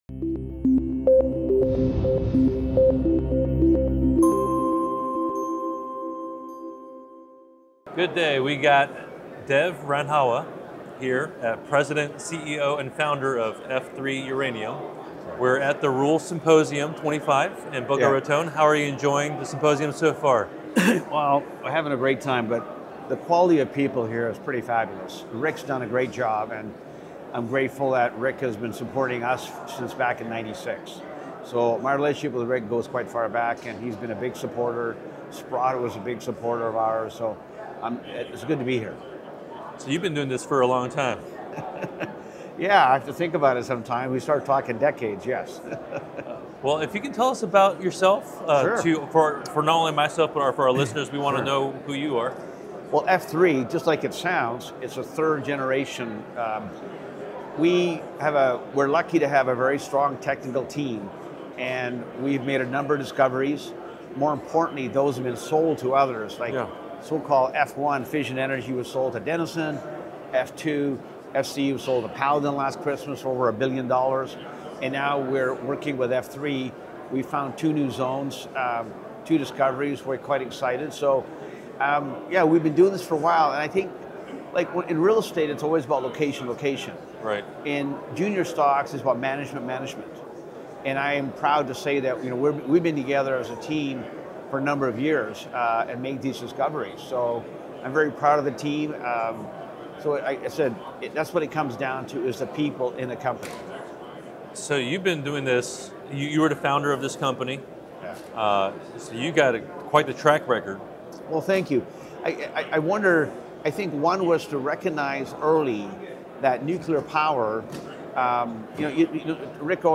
Interview
In this engaging interview at the Rule Symposium 25 in Boca Raton